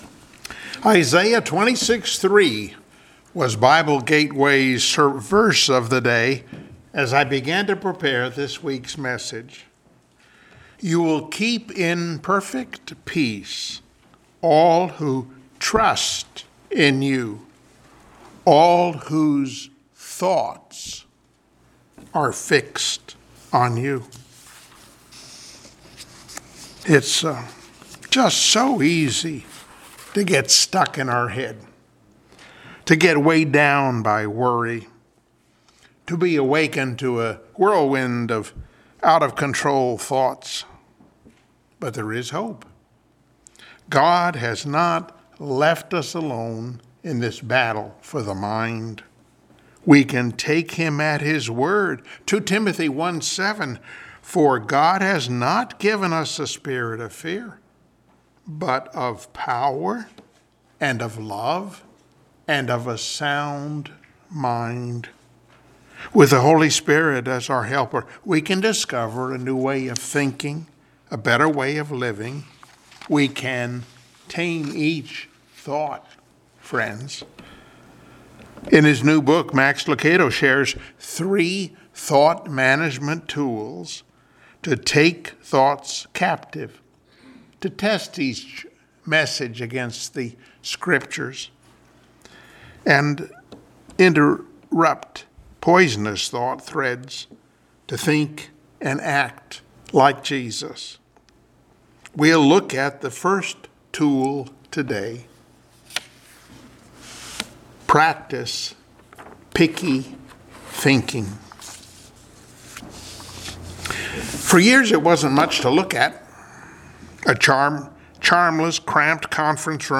Passage: 2 Corinthians 10:4-5 Service Type: Sunday Morning Worship